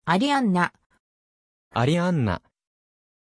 Pronuncia di Arianna
pronunciation-arianna-ja.mp3